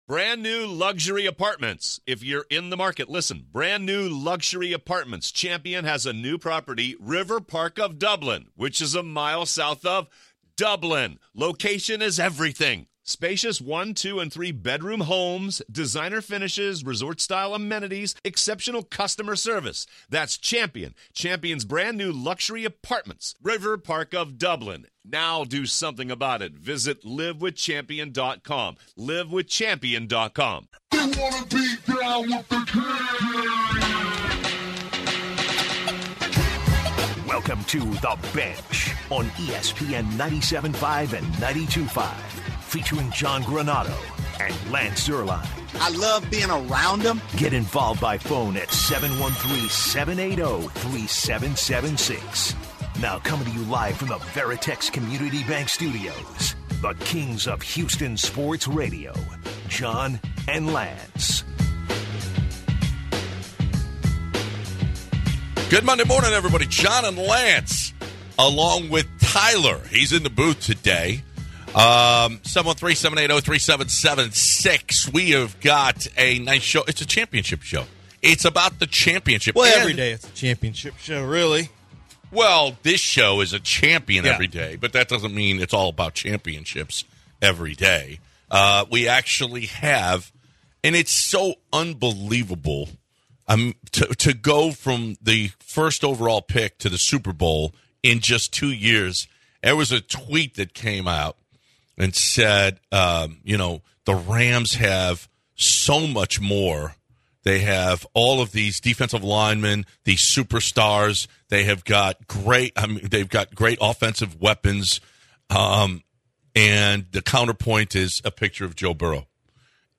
In the first hour of the show the guys recap and discuss the AFC and NFC Championship games and discuss the play of Joe Burrow, Matt Stafford, Patrick Mahomes and Jimmy Garoppolo. At the bottom of the hour the guys preview the super bowl and discuss the Bengals going from worst in the league to the Super Bowl in two years. The guys wrap up the first hour and talk to listeners to see what they learned over the weekend in one sentence.